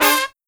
FALL HIT10-R.wav